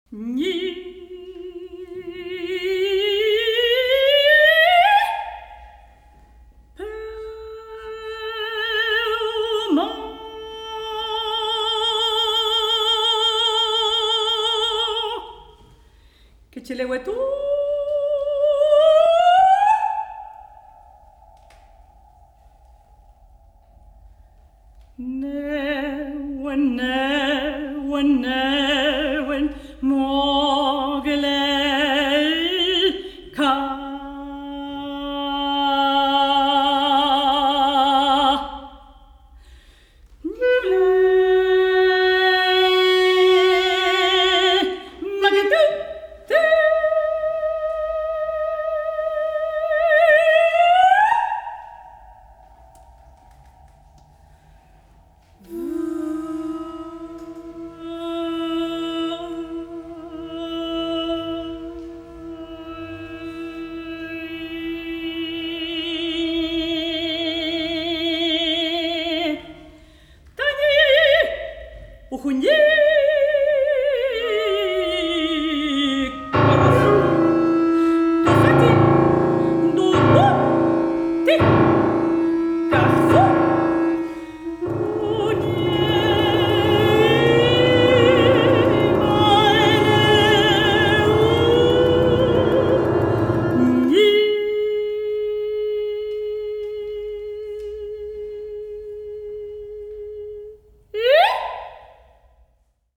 Epigramas Mapuches para contralto, clarinete, violín, cello y piano
Música vocal